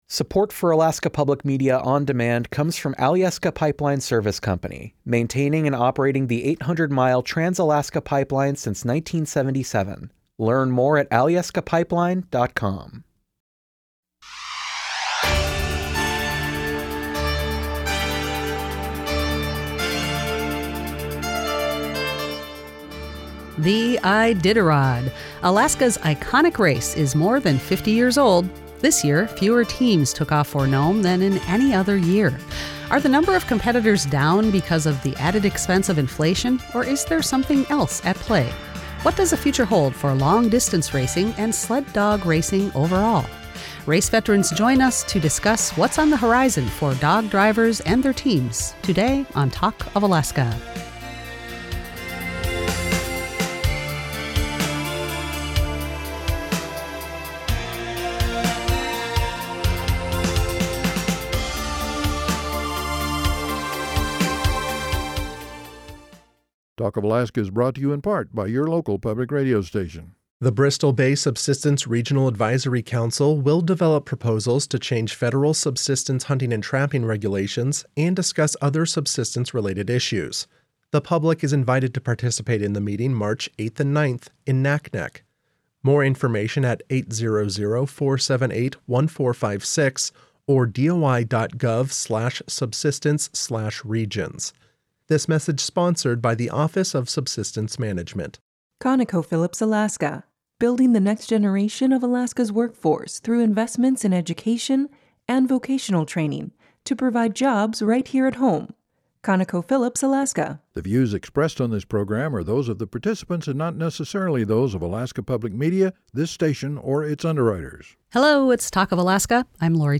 Are the number of competitors down because of the added expense of inflation or is there something else at play? What does the future hold for long distance racing and sled dog racing overall? Race veterans discuss what’s on the horizon for dog drivers and their teams on this Talk of Alaska